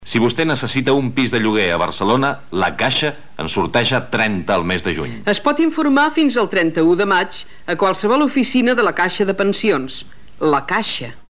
Publicitat del sorteig de pisos de La Caixa Gènere radiofònic Publicitat